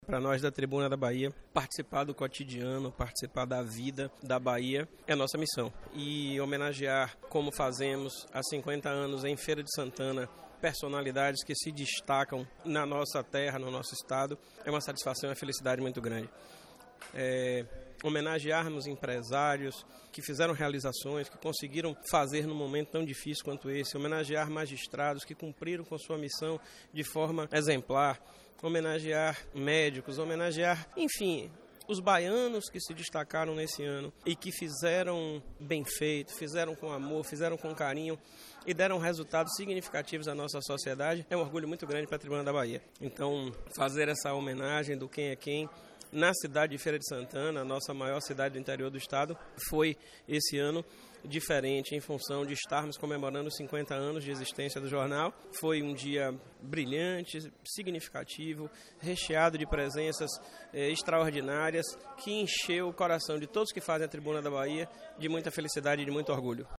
A solenidade ocorreu no Casarão Fróes da Mota, na cidade de Feira de Santana, no dia 12/12, e marcou o encerramento das comemorações pelos 50 anos do Jornal Tribuna da Bahia.